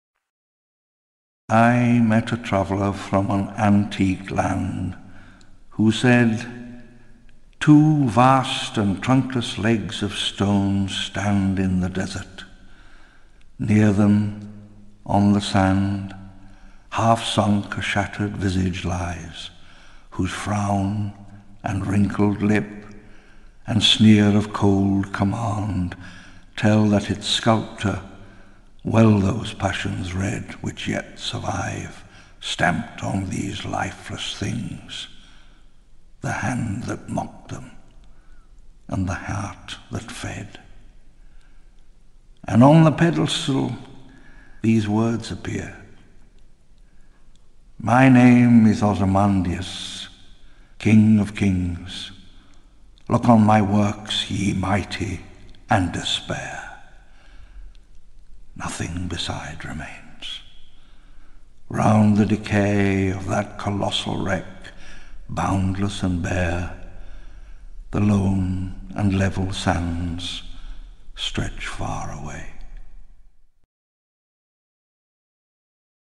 Richard Attenborough dit « Ozymandias » *
ozymandias-read-by-richard-attenborough.mp3